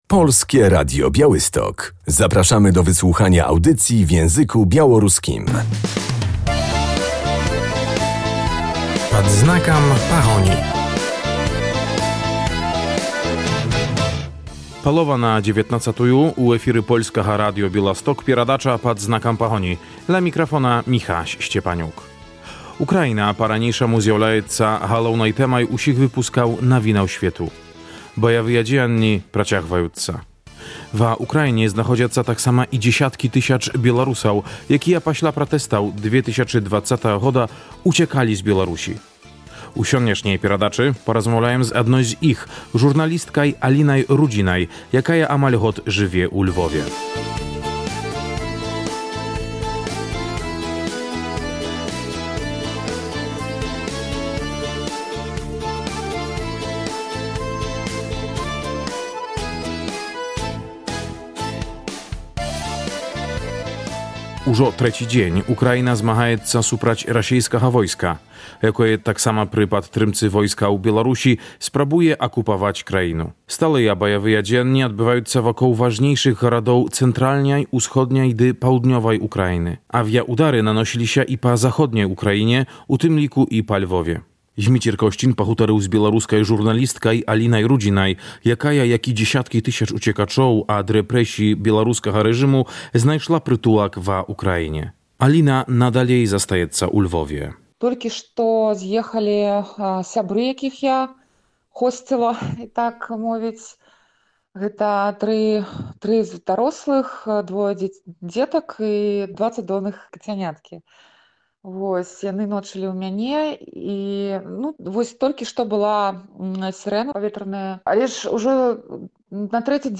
Uchodźcy z Białorusi na Ukrainie - rozmowa z Białorusinką we Lwowie26.02.2022